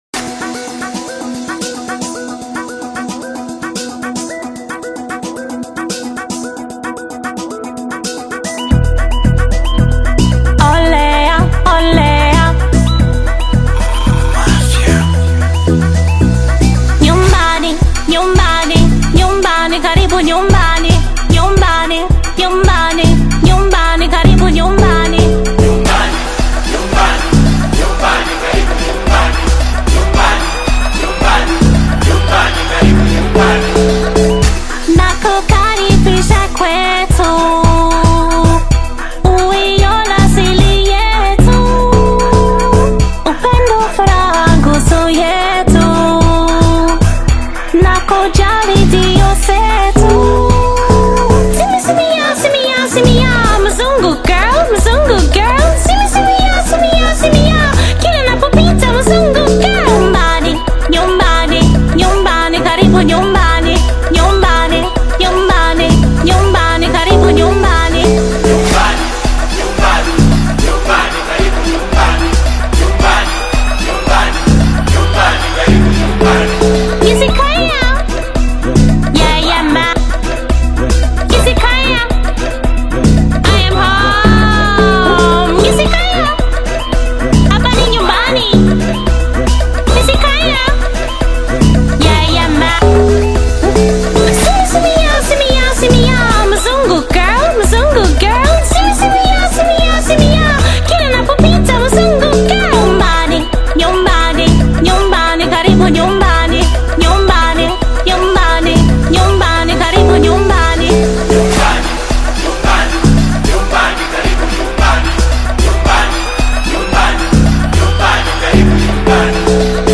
blending catchy melodies with meaningful songwriting.
Genre: Afro-Beats